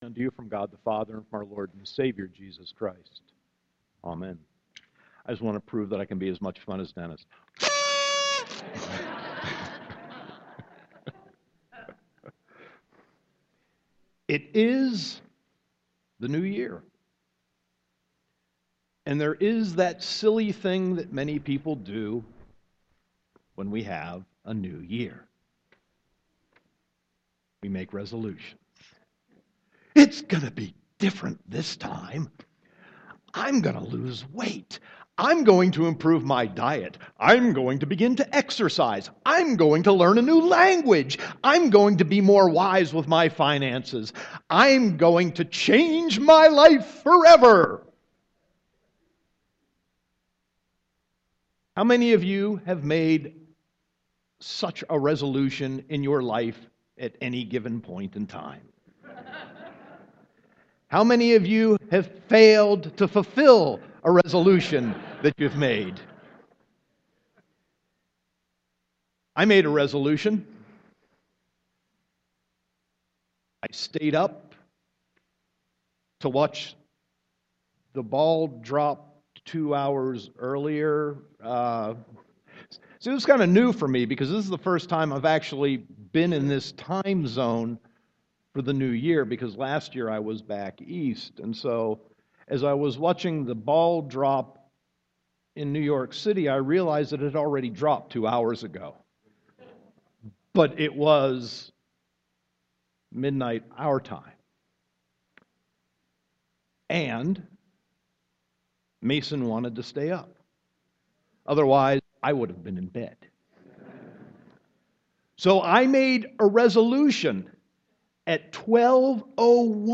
Sermon 1.3.2016